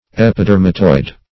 Search Result for " epidermatoid" : The Collaborative International Dictionary of English v.0.48: Epidermatoid \Ep`i*der"ma*toid\, a. [Gr.